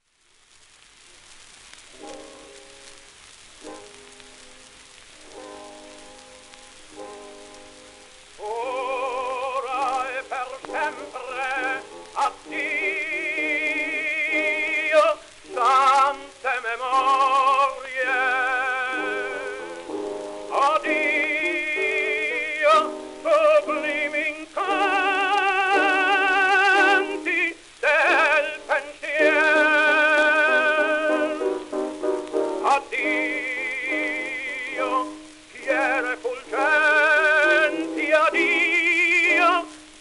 w/ピアノ
10インチ 片面盤
1903年録音
77rpm位